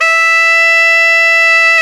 Index of /90_sSampleCDs/Roland L-CD702/VOL-2/SAX_Tenor mf&ff/SAX_Tenor mf
SAX TENORM0N.wav